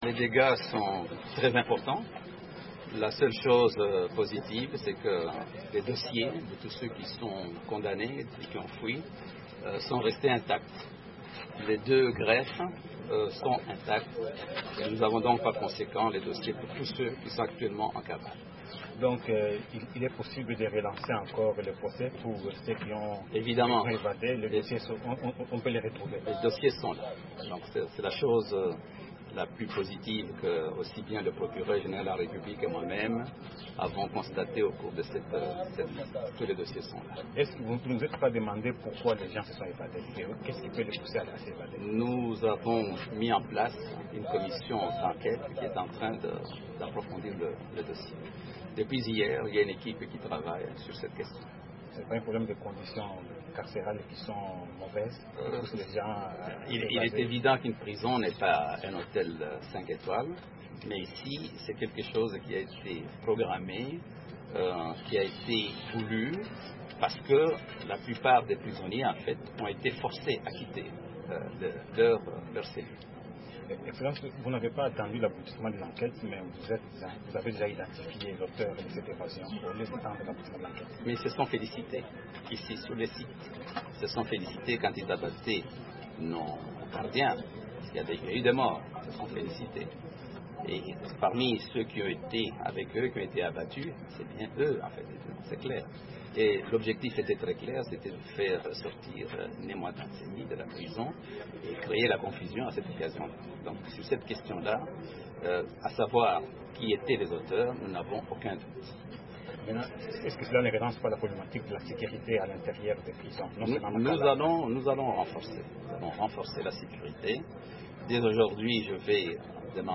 Déclaration du ministre Alexis Thambwe Mwamba et du Procureur général de la République en RDC